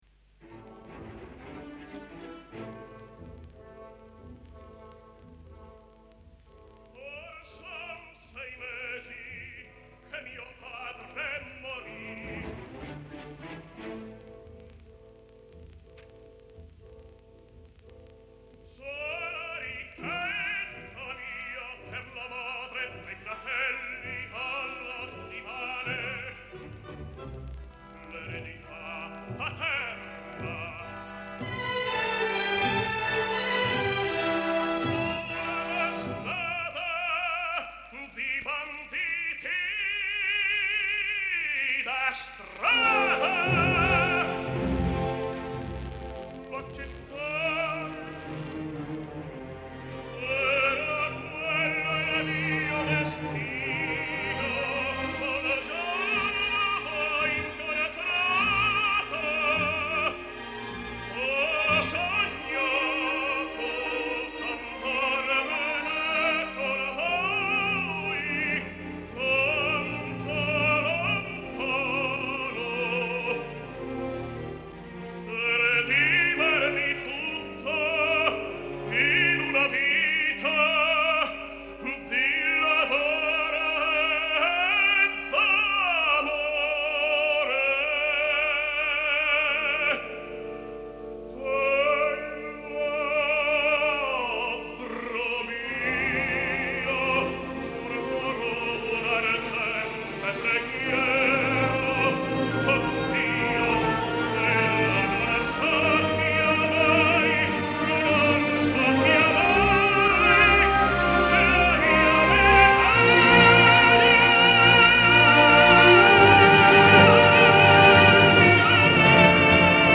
Несколько записей итальянского тенора Даниеле Бариони (1930 г.р).